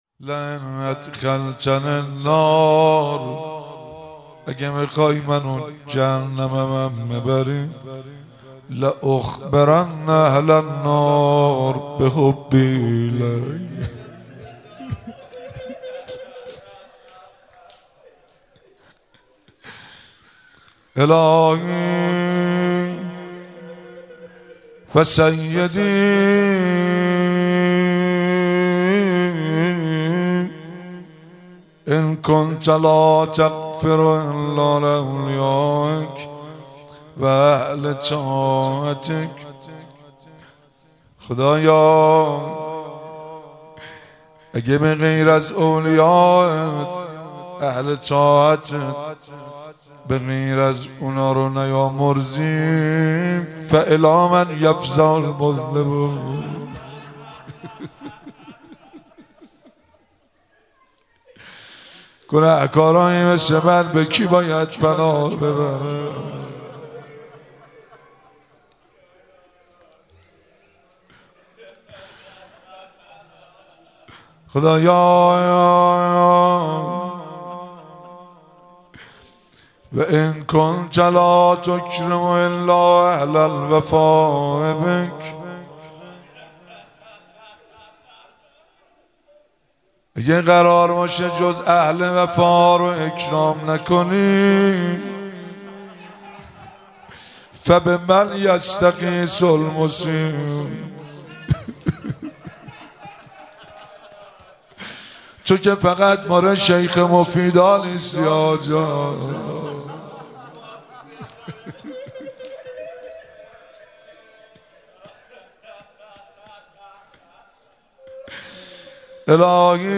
مراسم مناجات خوانی شب هجدهم ماه رمضان 1444